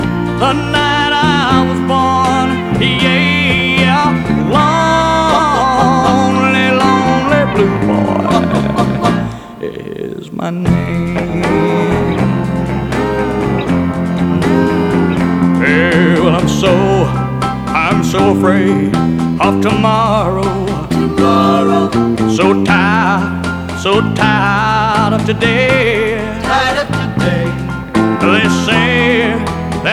Country
Жанр: Кантри